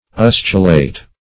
Search Result for " ustulate" : The Collaborative International Dictionary of English v.0.48: Ustulate \Us"tu*late\, a. [L. ustulatus, p. p. of ustulare to scorch, urere to burn.]
ustulate.mp3